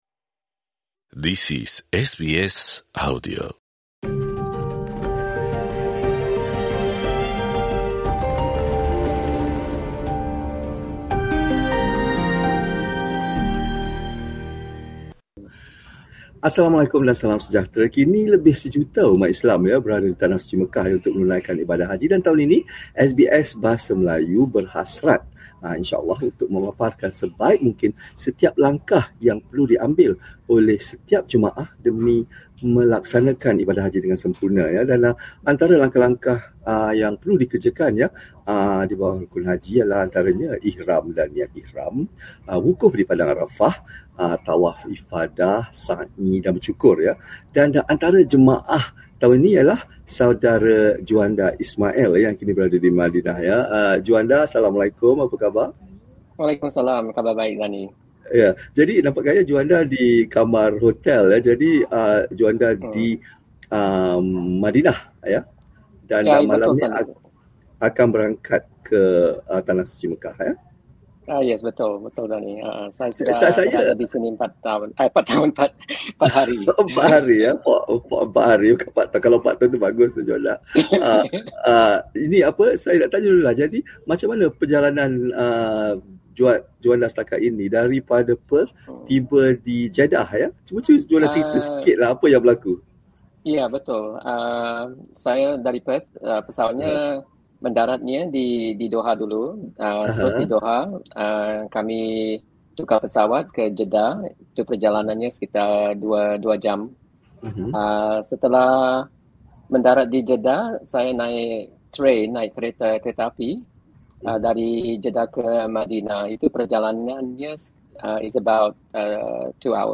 Di sini, beliau berpeluang menziarahi Makam Nabi Muhammad SAW di Masjid Nabawi, masjid kedua terpenting dalam Islam selain Masjidil Haram di Mekah. Ikuti perbualan